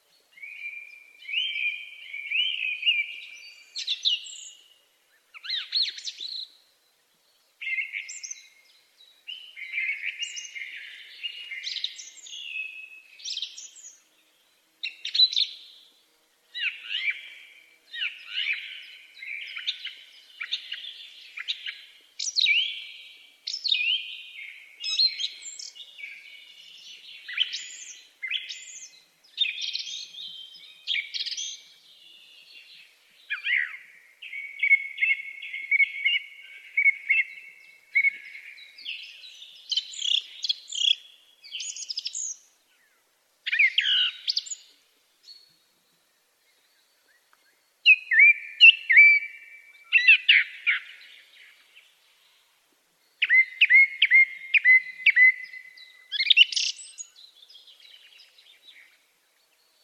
song thrush bird melodic song.wav
Original creative-commons licensed sounds for DJ's and music producers, recorded with high quality studio microphones.
song_thrush_bird_melodic_song-2_u9b.mp3